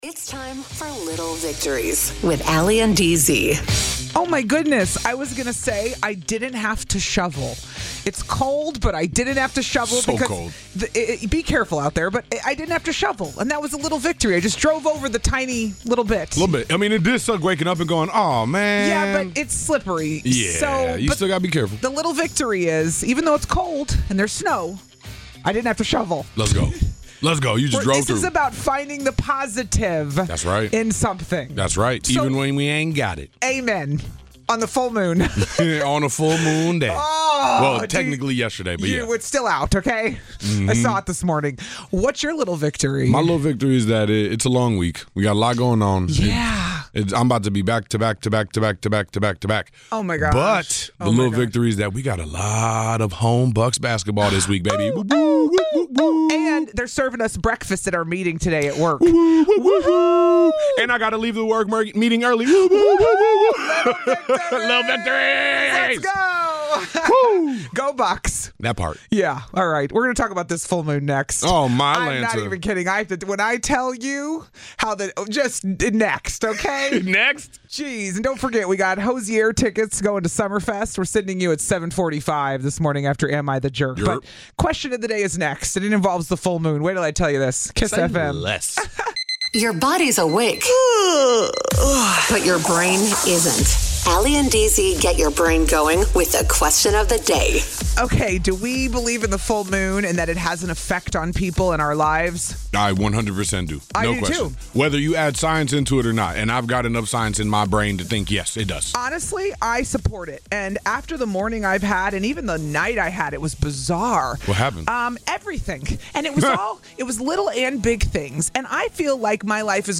No commercials, no music.